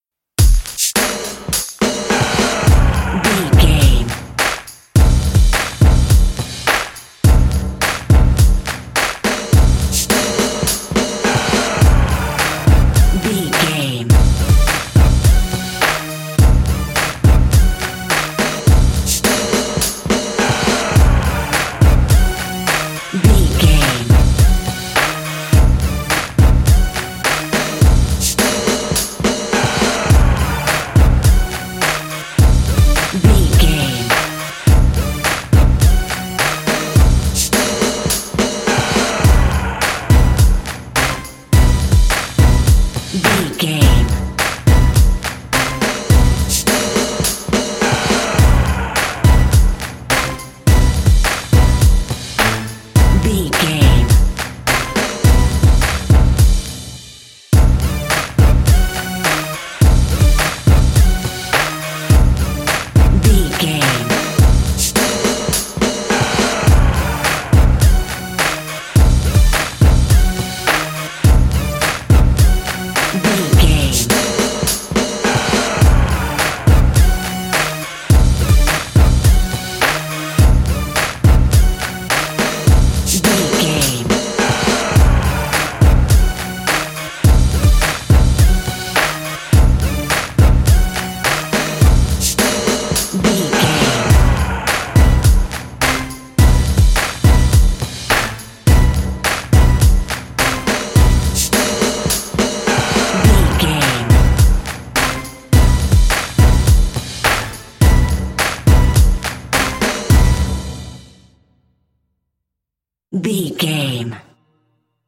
Aeolian/Minor
drum machine
synthesiser